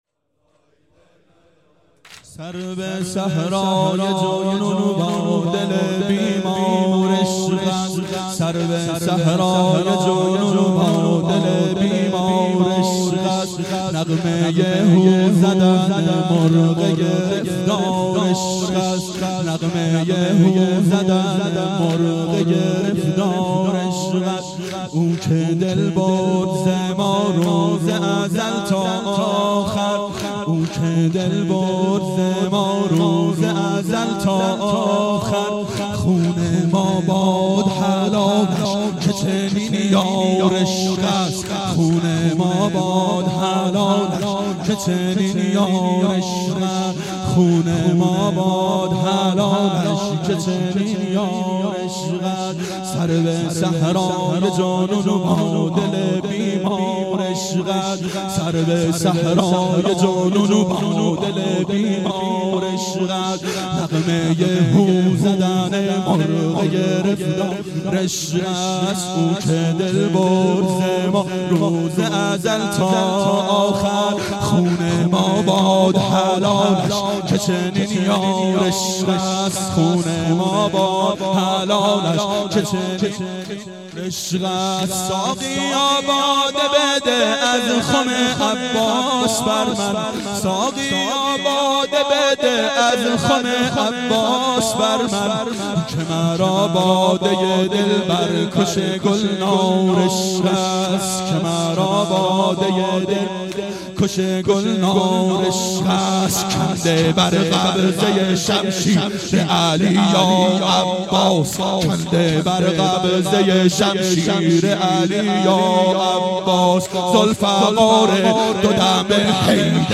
دهه اول صفر سال 1391 هیئت شیفتگان حضرت رقیه سلام الله علیها (شب شهادت)